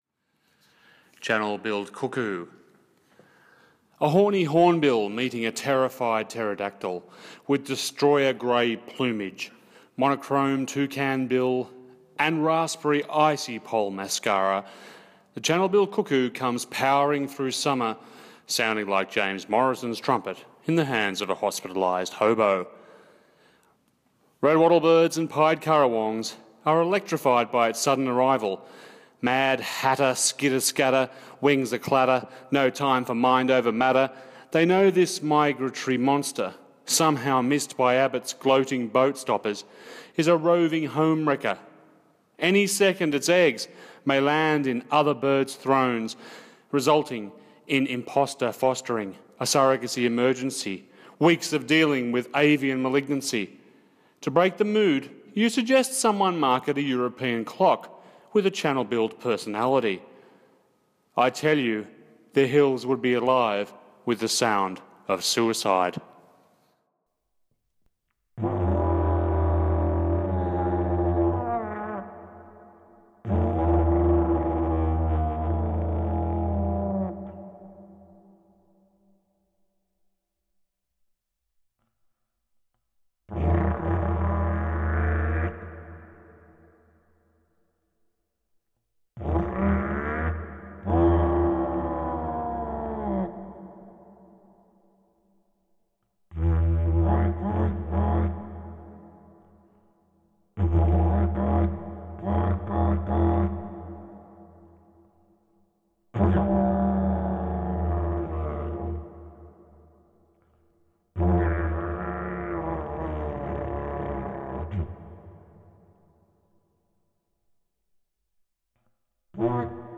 didjeridu